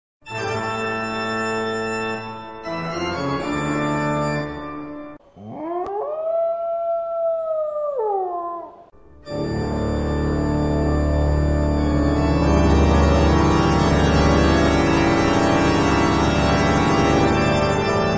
Catégorie Marimba Remix